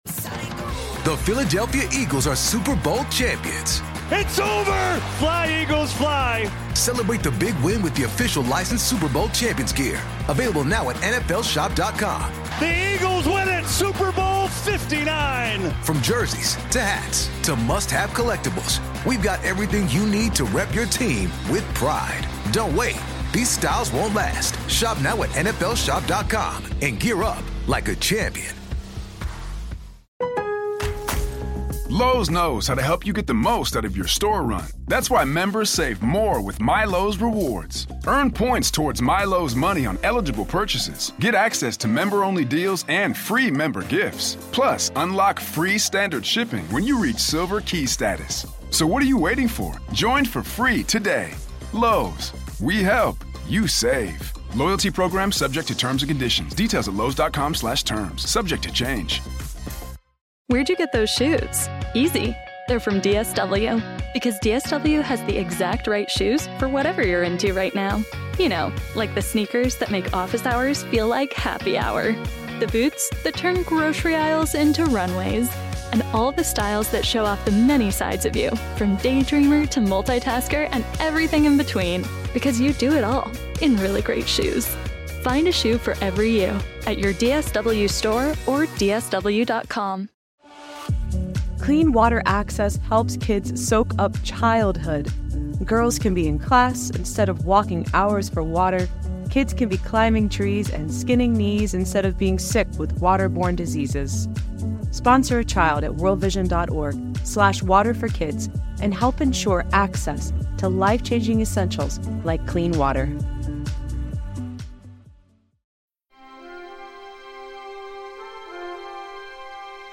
As head coach of the Dallas Cowboys for 29 years, Tom Landry compiled an impressive record of 13 division titles, five Super Bowl appearances, and two championships. The Cowboys would eventually be dubbed "America's team" and Landry became a coaching legend. Here he is with his story.